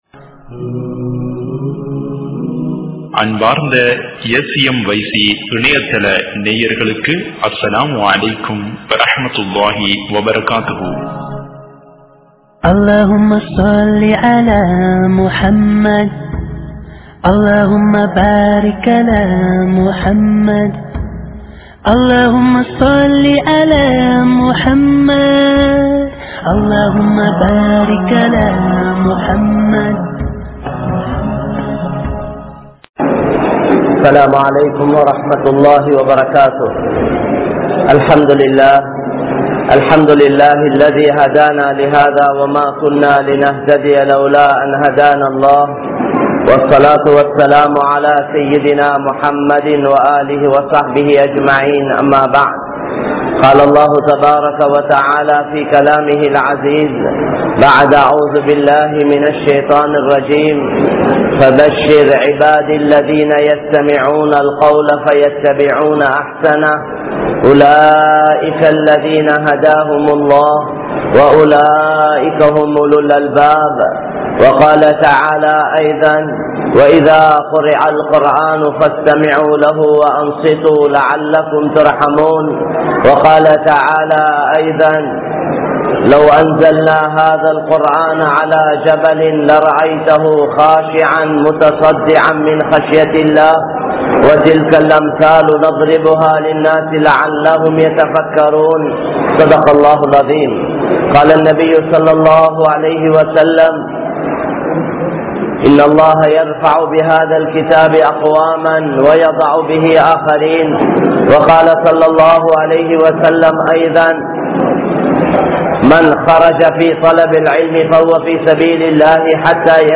Veettu Soolalai Seeraakkungal (வீட்டு சூழலை சீராக்குங்கள்) | Audio Bayans | All Ceylon Muslim Youth Community | Addalaichenai